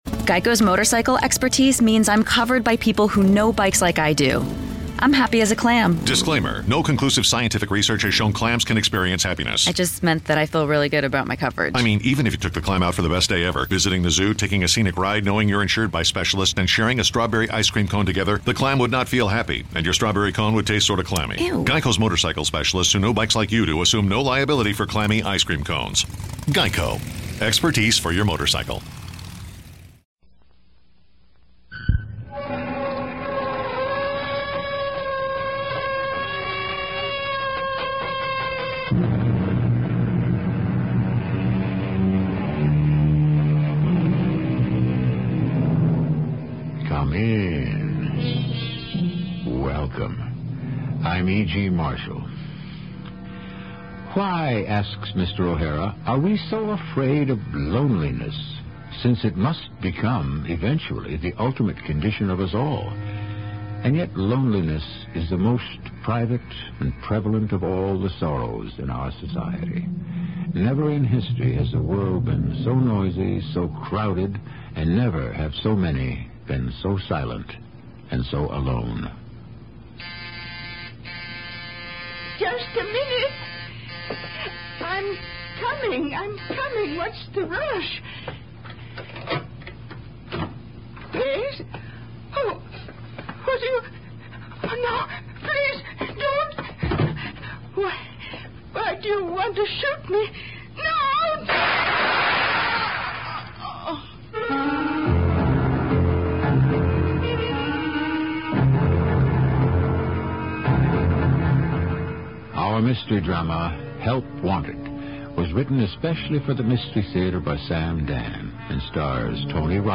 radio drama series